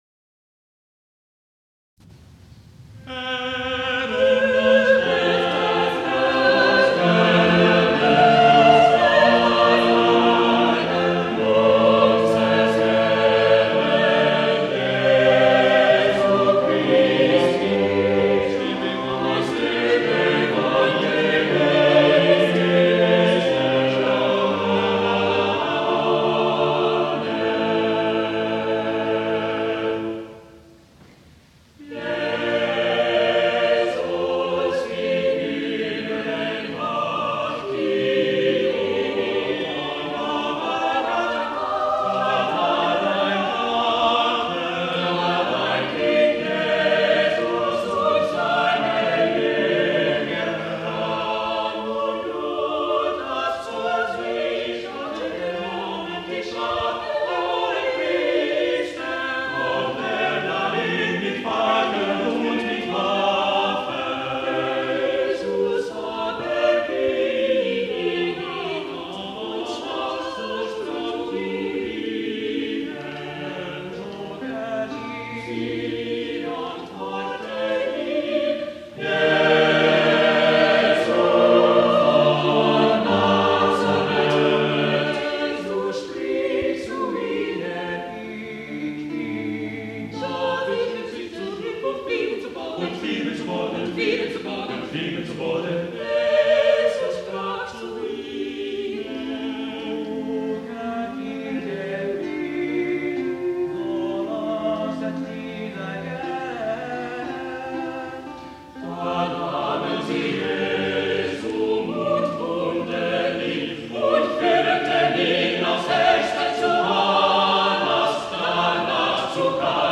It is a substantial setting of the ‘Passion nach dem Evangelisten Johannes’; six voices are used throughout. Three different arrangements of voices and instruments are employed in this performance: 1) full vocal and instrumental ensemble forces are used for the introductory and closing music, and for the clamoring of the crowd, 2) vocal ensemble alone is used for the Evangelist’s narration, 3) a solo voice with instruments is used for dialogue.